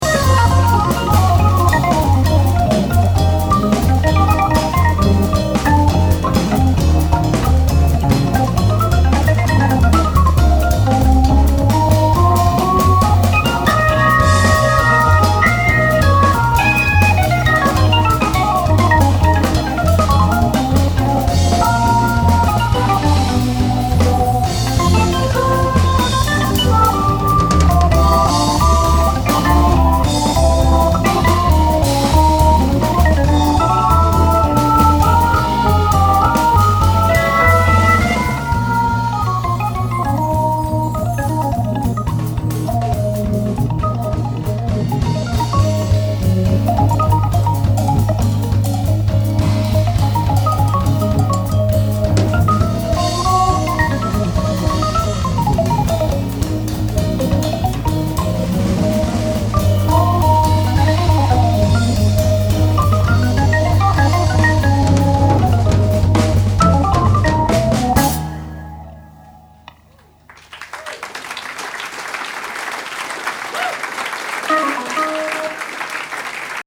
Tout l’album est en live ?
batterie